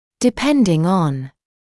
[dɪ’pendɪŋ ɔn][ди’пэндин он]в зависмости от